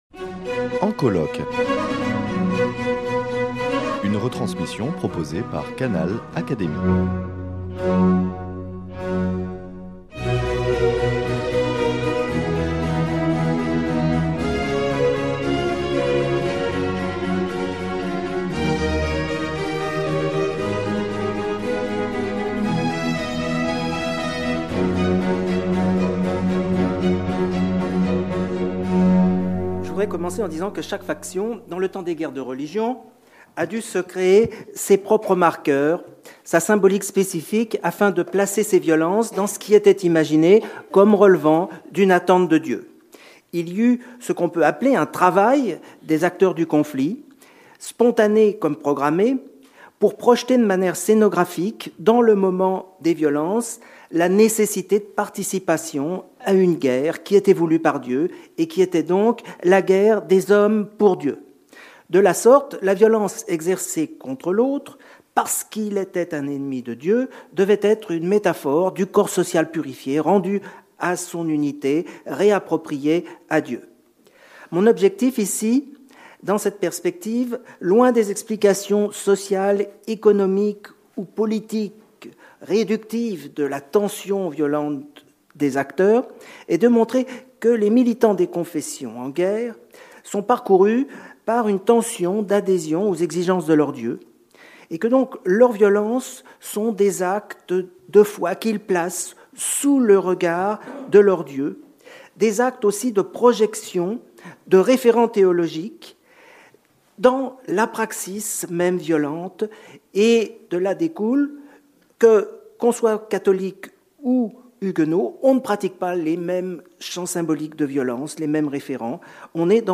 lors du colloque « Guerre et société », à la Fondation Simone et Cino del Duca.